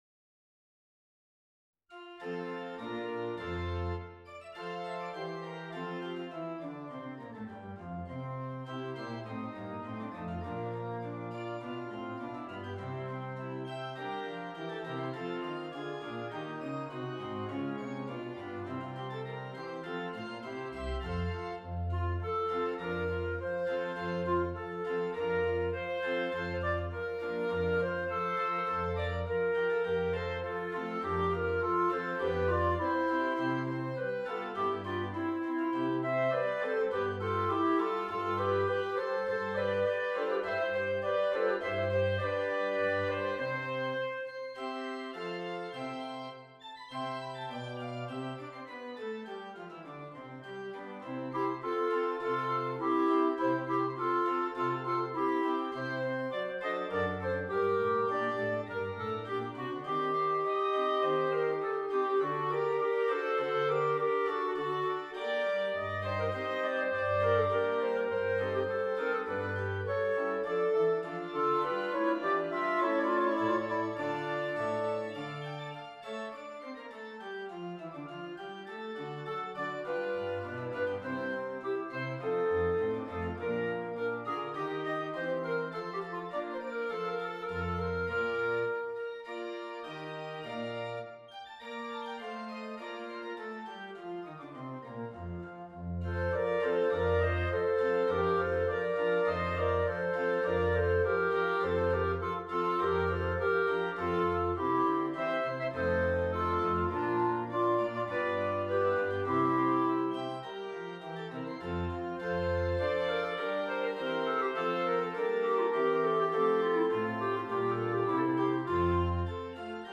2 Clarinets and Keyboard
2 clarinets and keyboard (organ or piano)